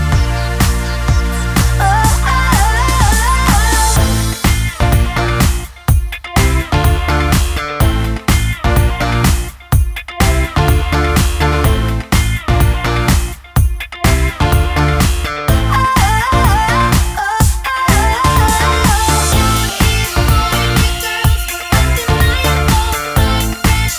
Extra Backing Vocals In Chorus Pop (2000s) 3:54 Buy £1.50